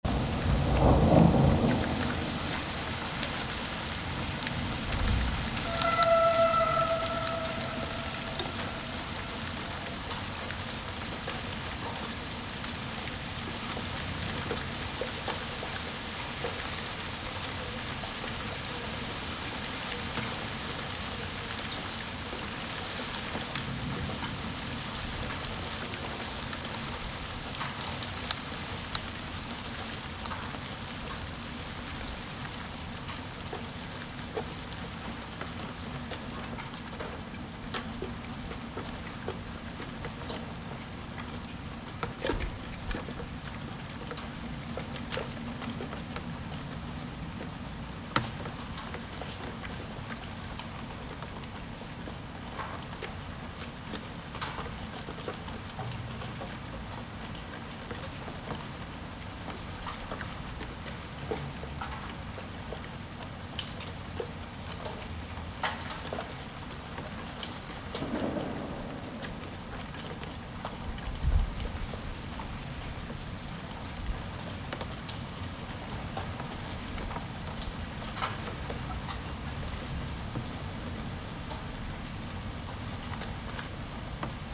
Hail and rain | Zvuky Prahy / Sounds of Prague
field recordings, sound art, radio, sound walks
Kroupy a déšť
Tagy: voda okno doma vlaky počasí
Kroupy a déšť neobvyklého úkazu – lednové bouře – zachyceny, jak dopadají na parapet a dlažbu dvoru ve vnitrobloku.
moskevska_hail_and_rain.mp3